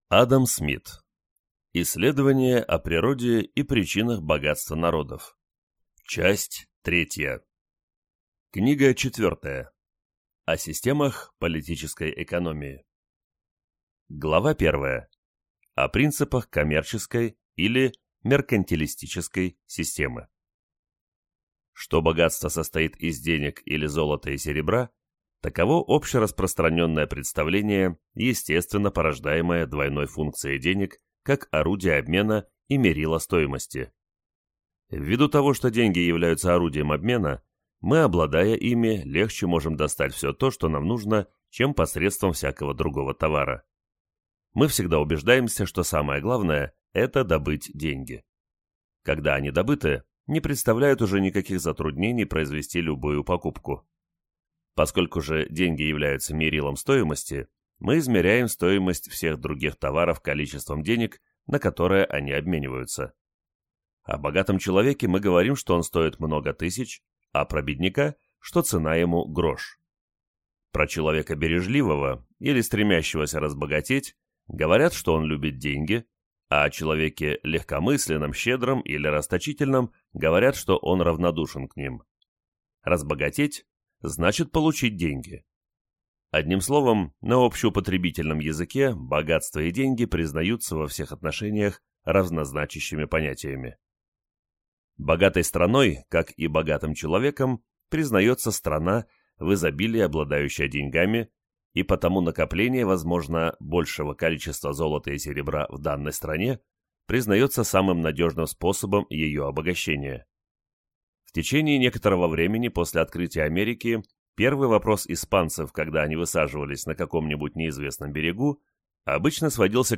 Аудиокнига Исследование о природе и причинах богатства народов (Часть 3) | Библиотека аудиокниг